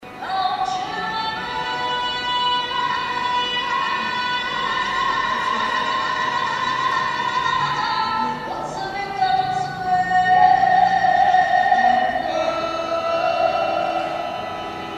Manifestările dedicate romilor de pretutindeni au fost organizate miercuri,  în sala de Consiliu Local a Primăriei Timișoara și ar fi trebuit să înceapă cu intonarea imnului internațional al rromilor.
După ce filmulețul a fost găsit pe internet, imnul a fost reluat: